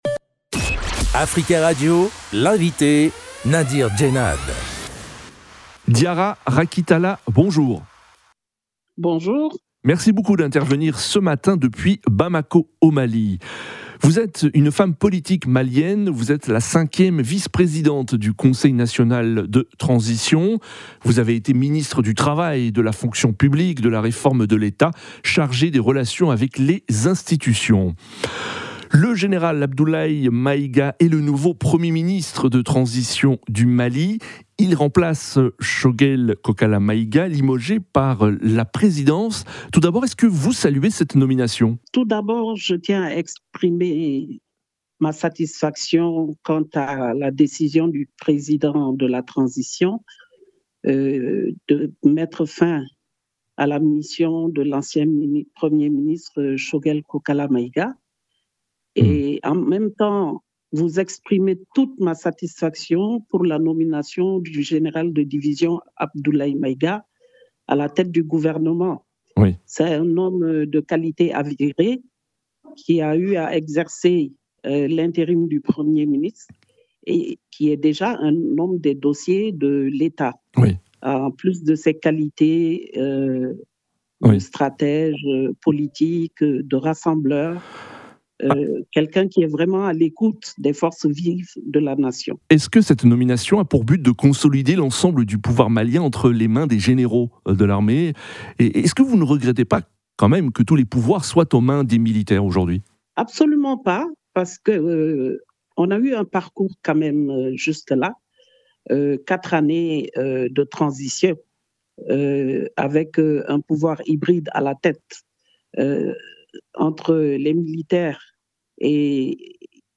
Elle était l'invitée d'Africa Radio lundi 25 novembre 2024 à 07h45.